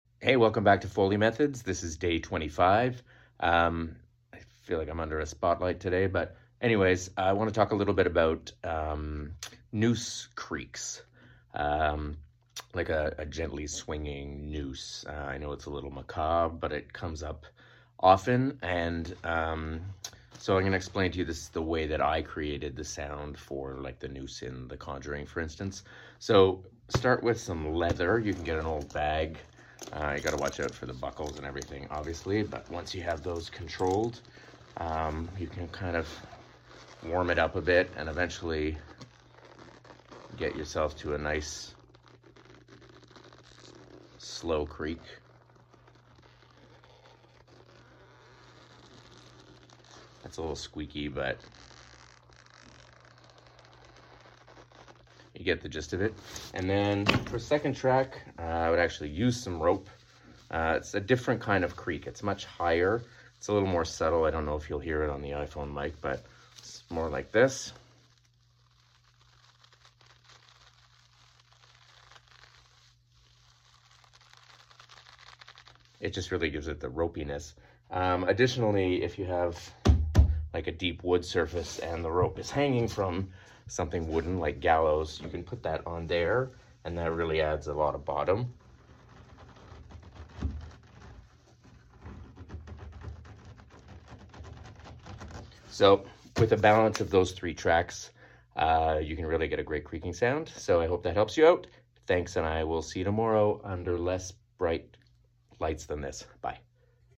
Day 25 of sharing some basic Foley artist techniques and info with you!